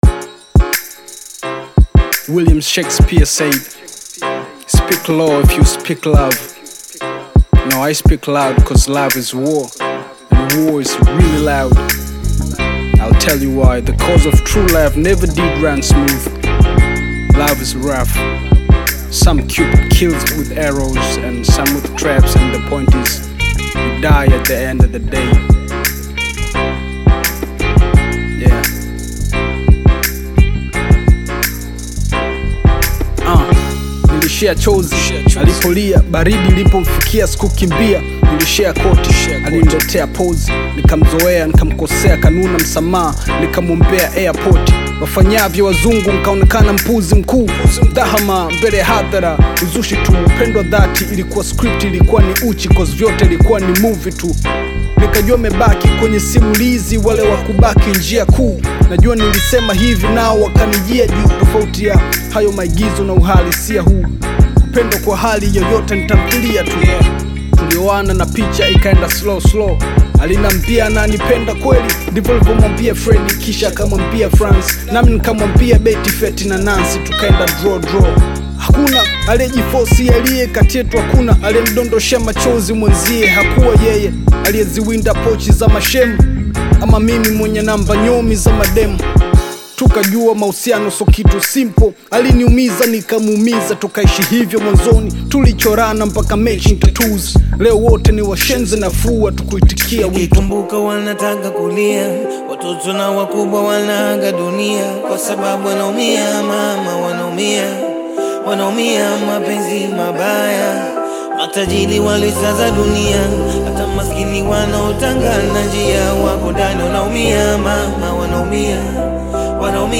Bongo Flava You may also like